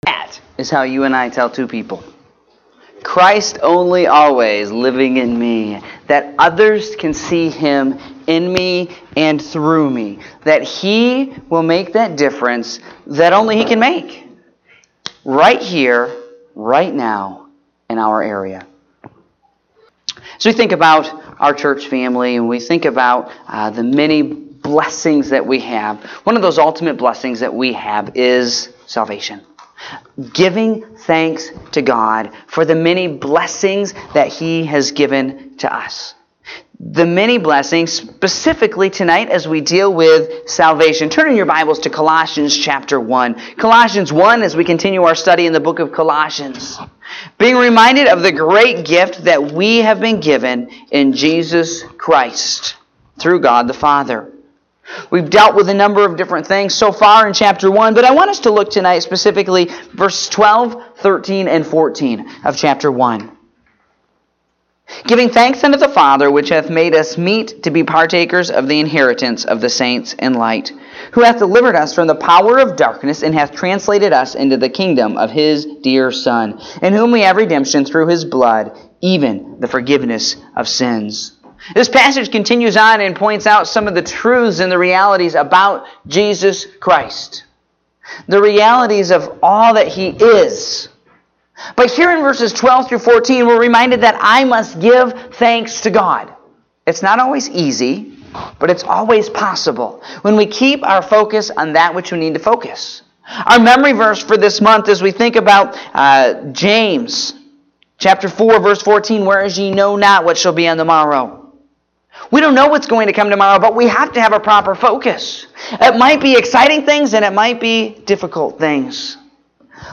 Evening Service (3/04/2018)